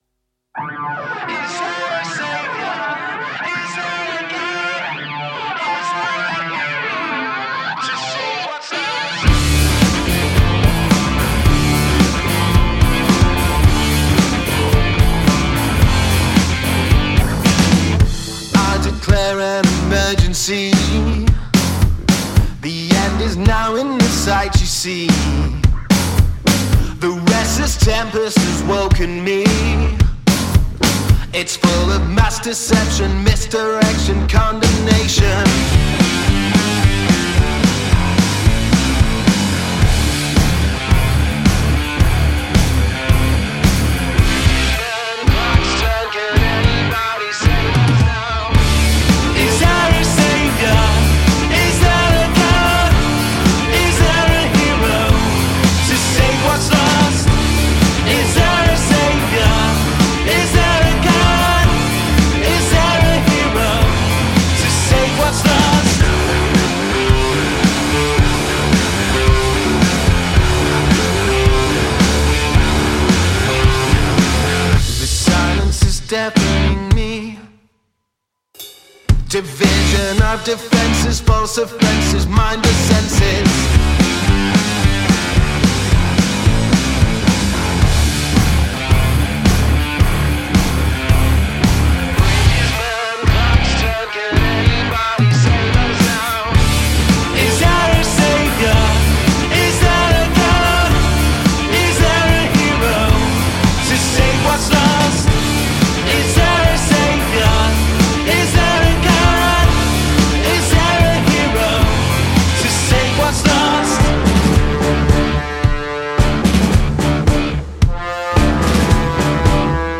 Male Vocal, Electric Guitar, Synth, Bass Guitar, Drums